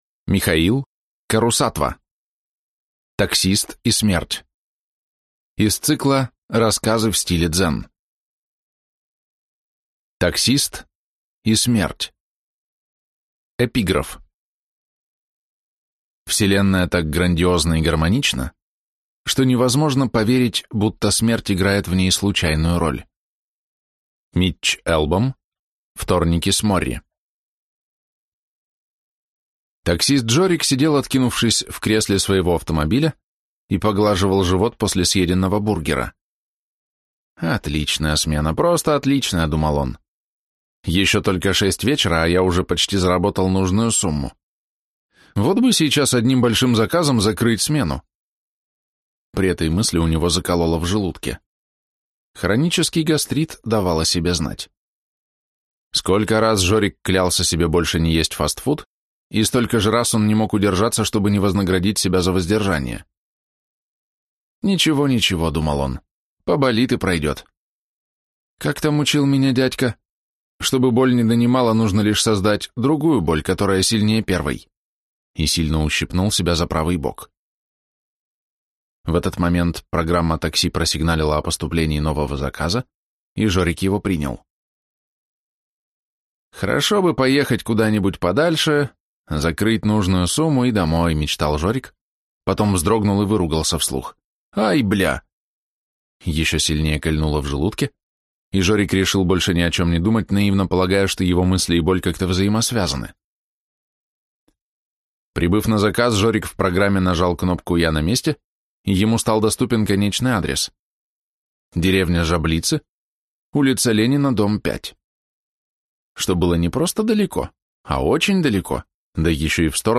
Аудиокнига Таксист и смерть. Из цикла «Рассказы в стиле Дзен» | Библиотека аудиокниг